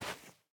Minecraft Version Minecraft Version snapshot Latest Release | Latest Snapshot snapshot / assets / minecraft / sounds / block / powder_snow / break7.ogg Compare With Compare With Latest Release | Latest Snapshot
break7.ogg